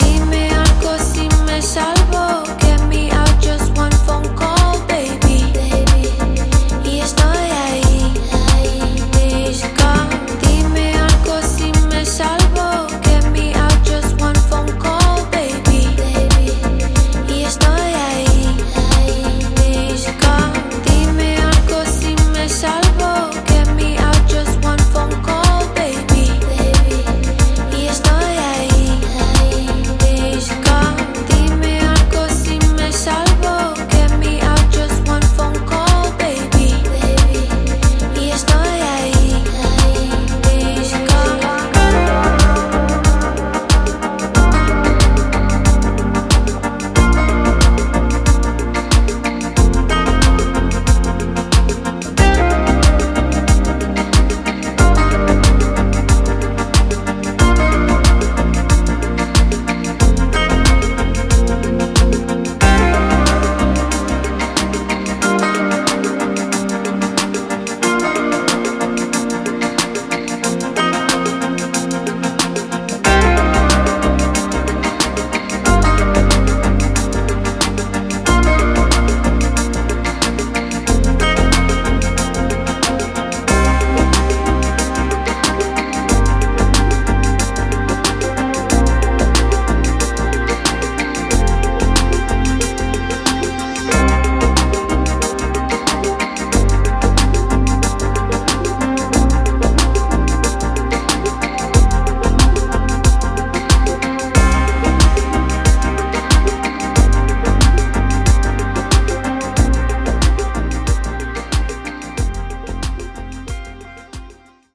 A1: Original Mix